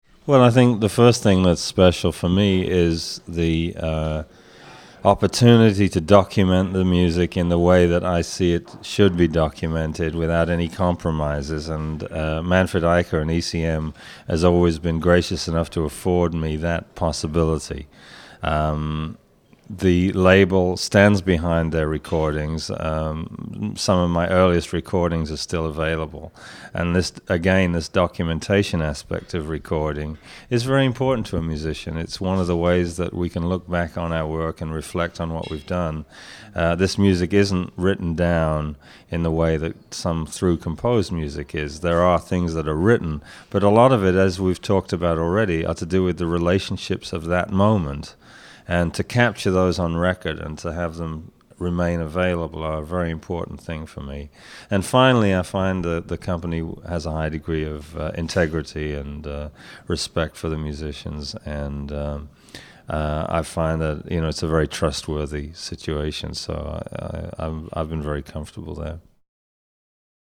acoustic and electric bass